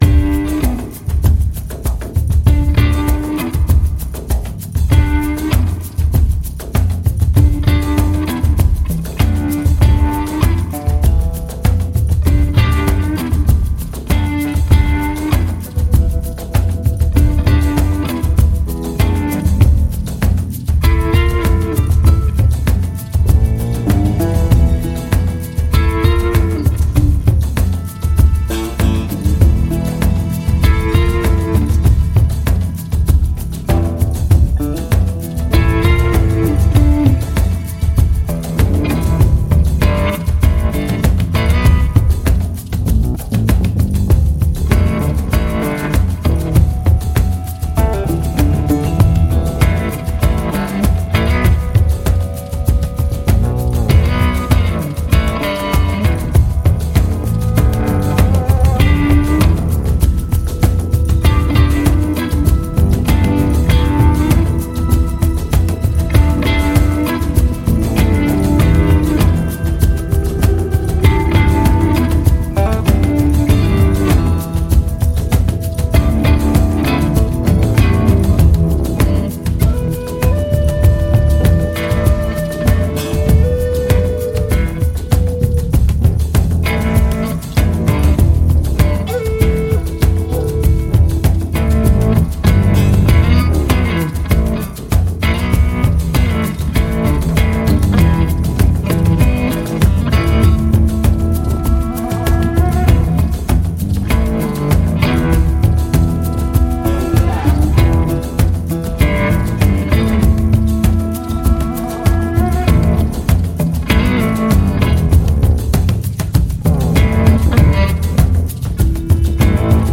L’expérience musicale est presque hypnotique.